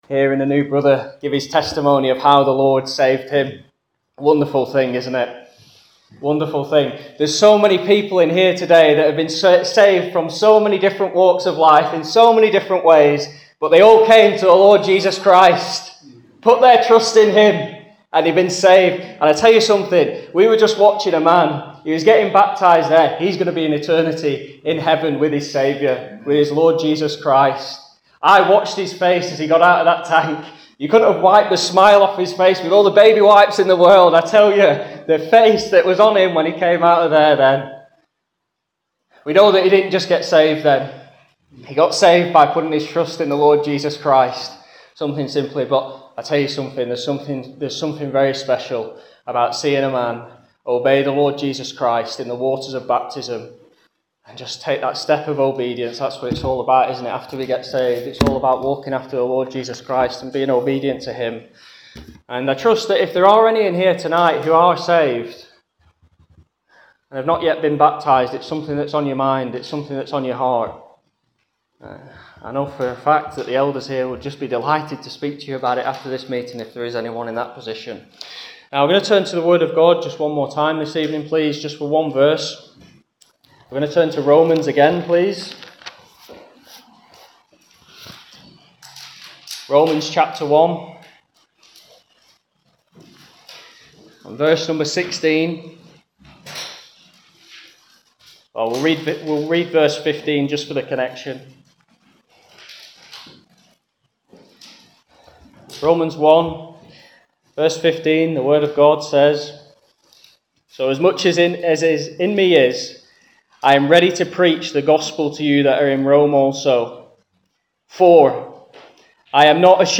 Gospel Meetings